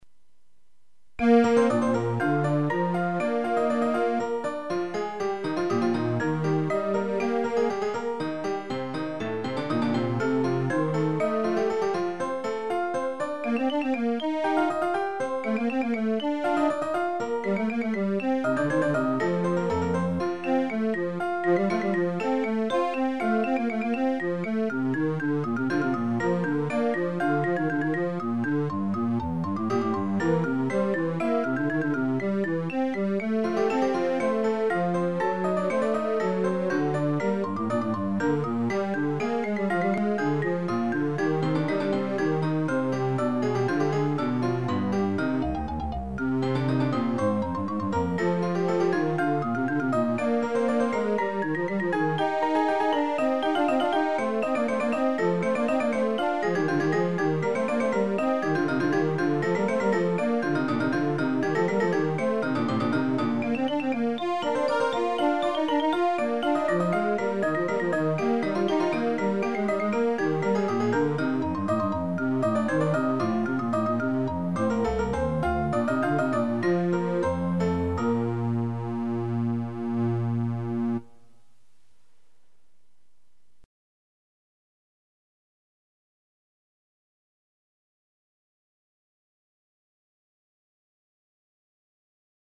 The MP3 was made by playing the MIDI file simultaneously on an SC-55 and a Yamaha TG33.
'THIS CAUSES ALL top NOTES TO SOUND LIKE A PIANO
SEE gm.zel FOR A COMPLETE LIST 'SIMILARLY CREATE A TRACK CALLED bass AT OCTAVE 4, 'AND CHANNEL 2 : track bass octave 4 channel 2 'SEND A PROGRAM CHANGE WITH VALUE OF 12: patch 12 '...CAUSING ALL bass NOTES TO SOUND LIKE THE MARIMBA! 'SET THE DEFAULT NOTE LENGTH (DURATION UNIT) 'TO ONE EIGHTH NOTE: duration /8 'SET THE TEMPO TO 60 BEATS PER MINUTE: tempo=60 'SET THE KEY TO E FLAT: key E& 'IN THIS SECTION WE DEFINE SOME MACROS 'A MACRO IS SIMPLY A WAY OF REPLACING ONE STRING 'OF LETTERS WITH ANOTHER.